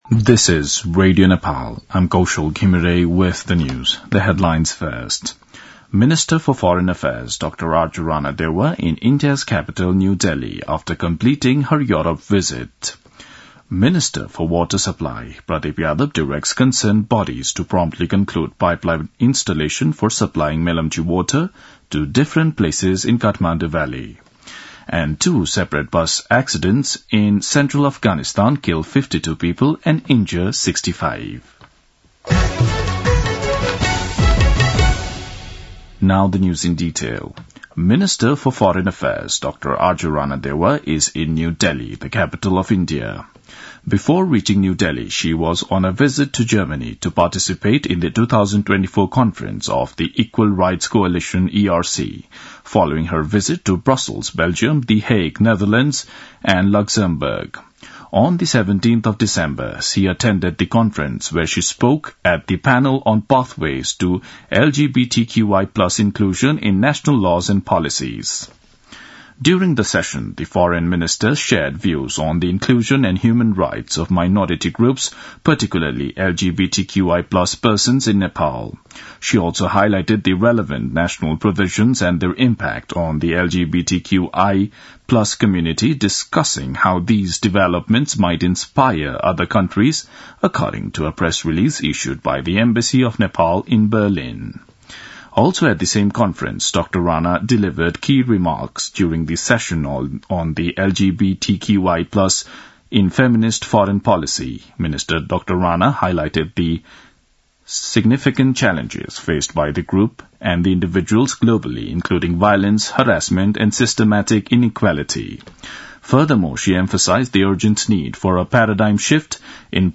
दिउँसो २ बजेको अङ्ग्रेजी समाचार : ५ पुष , २०८१
2-pm-english-news-1-12.mp3